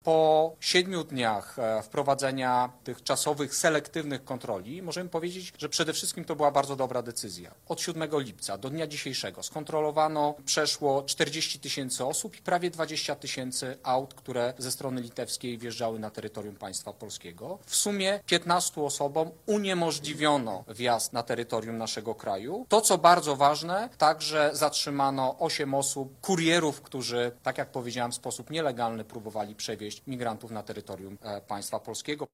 W ciągu pierwszego tygodnia kontroli na granicy polsko-litewskiej skontrolowano ponad 40 tysięcy osób – poinformował na specjalnej konferencji prasowej wojewoda podlaski Jacek Brzozowski.